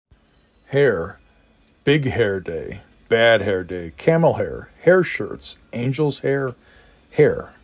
h e r
h air